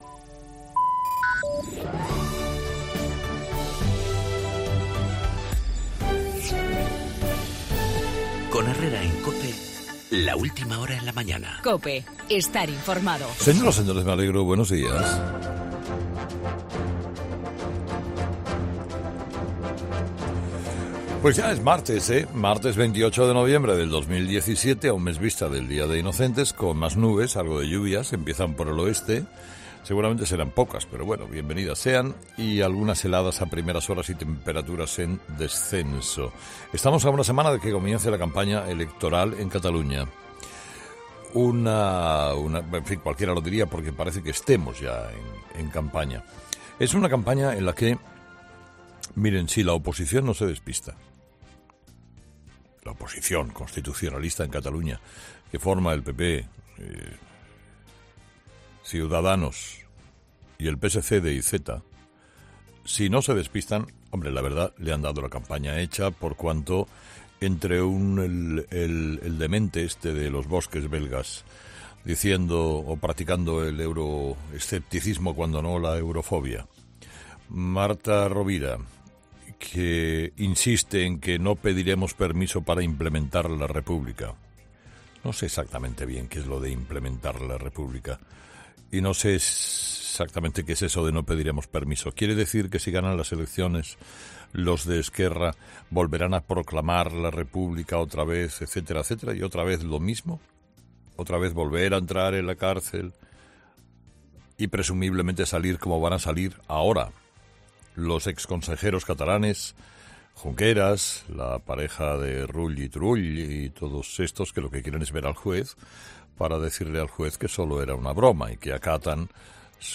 Monólogo de las 8 de Herrera
Con Carlos Herrera